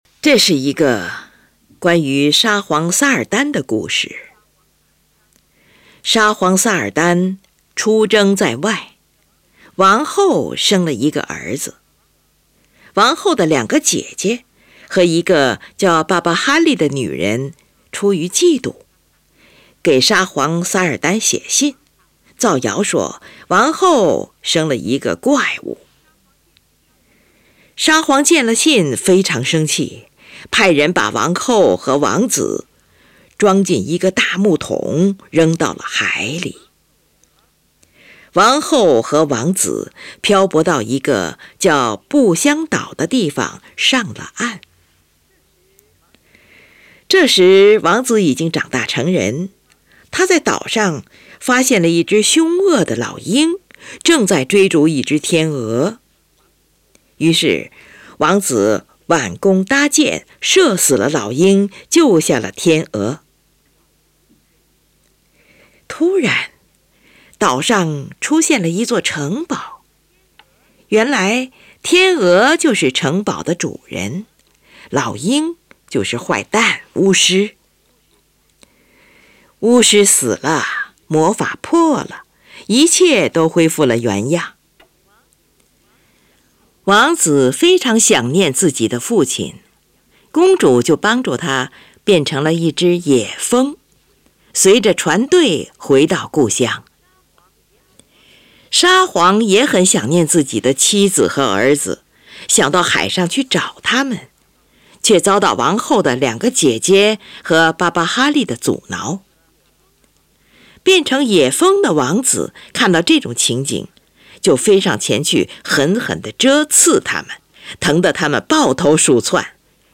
最后是半音节上升的乐句，描写黄蜂向远处飞去的情景。